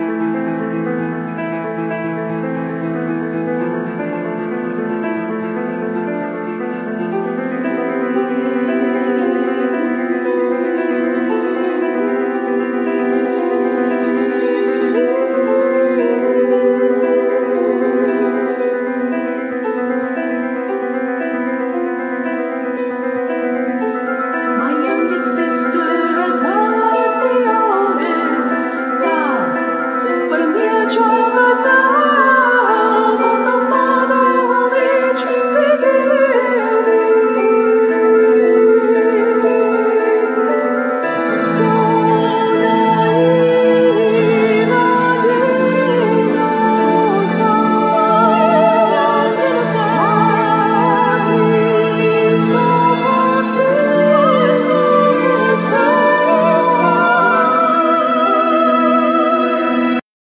All instruments,Voice
Voice